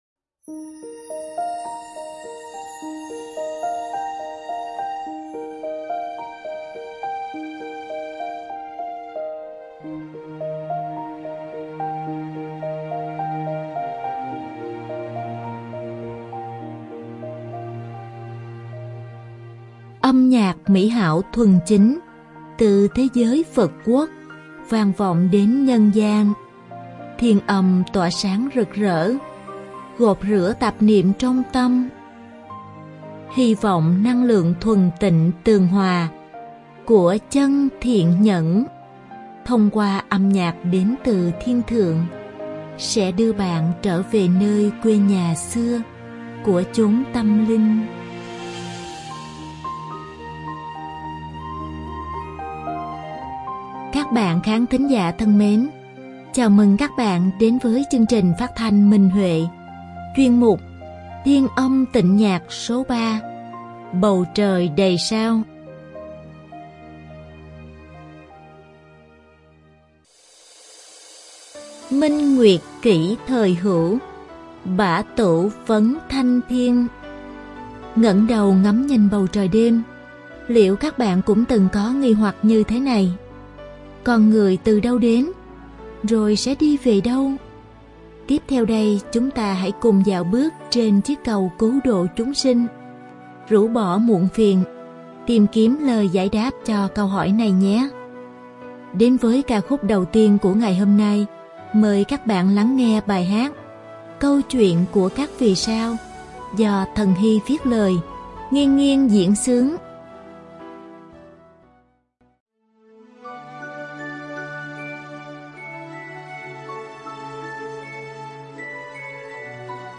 Độc tấu đàn tam thập lục
Đơn ca nữ